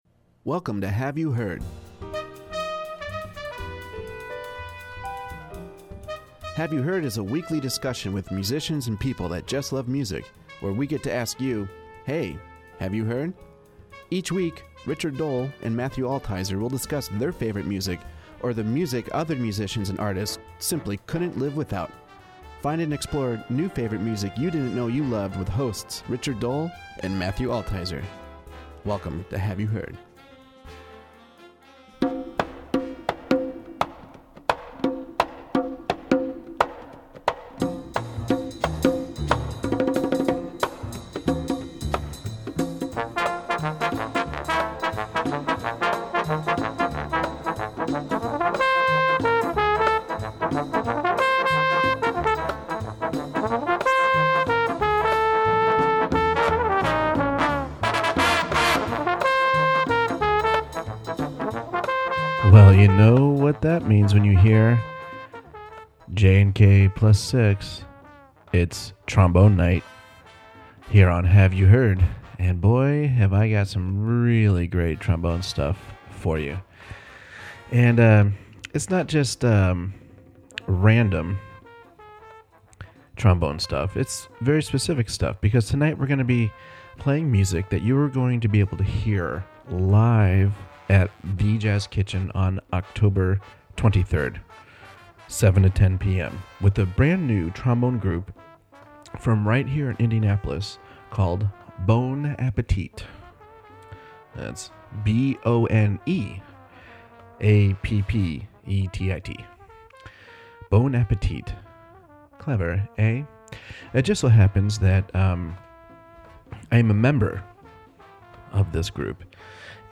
The new trombone led band
It’s a fun band playing some really wonderful arrangements.